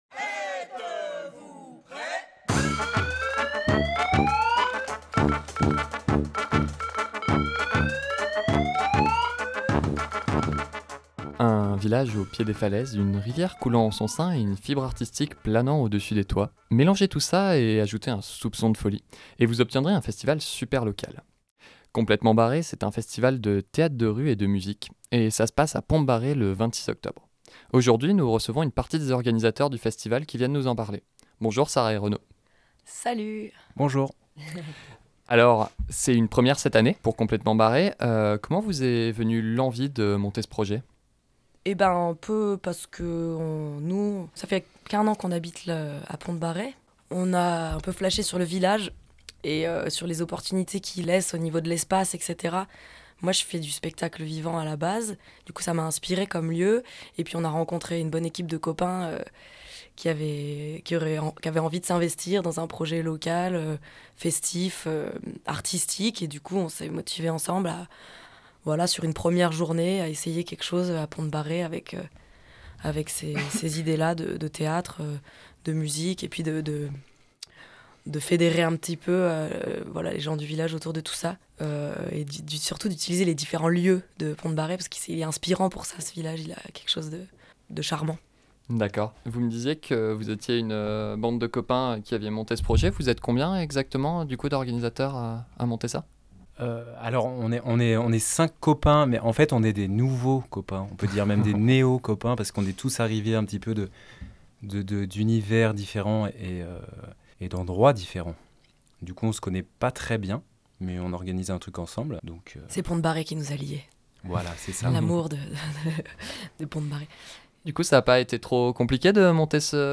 21 octobre 2019 10:39 | Interview